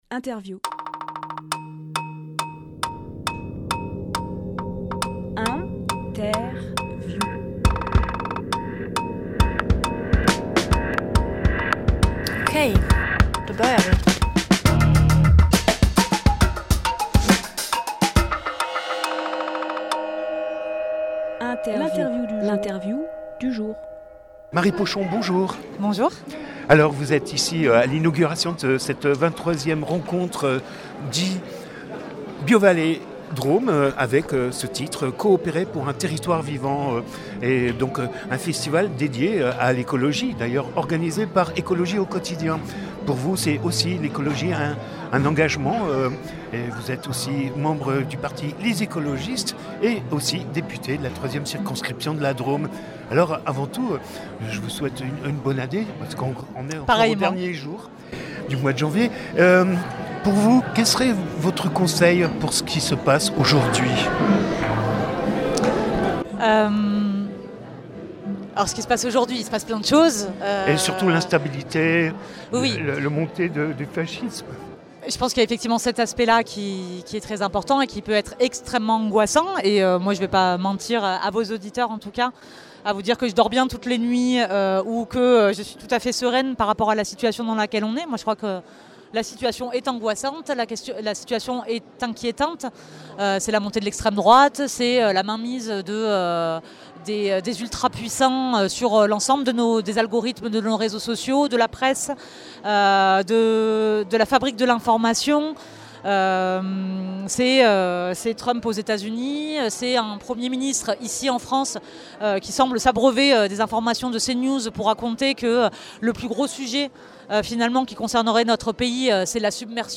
Emission - Interview Coopérer pour un territoire vivant : Marie Pochon Publié le 11 février 2025 Partager sur…
lieu : Salle polyvalente